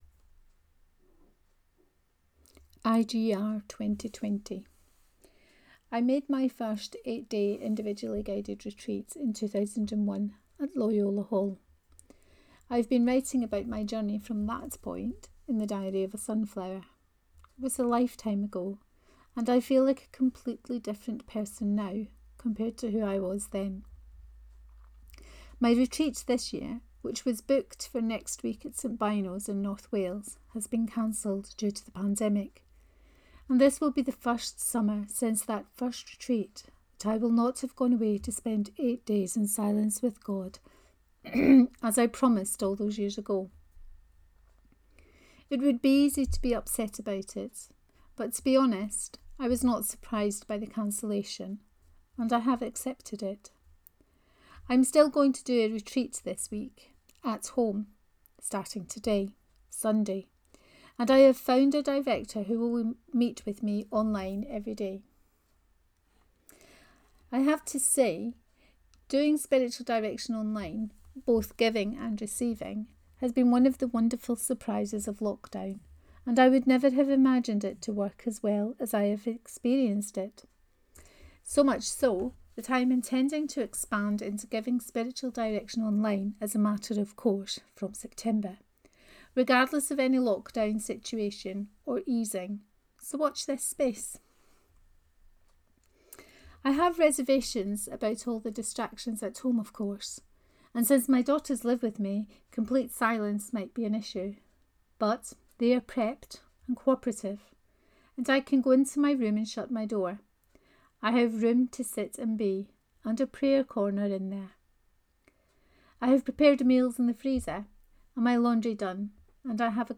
IGR 2020 1: Reading of this post.